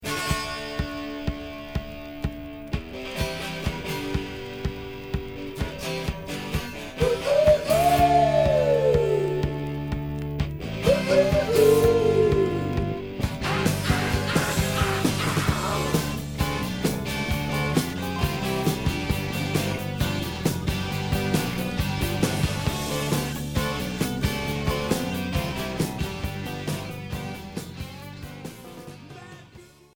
Rock glam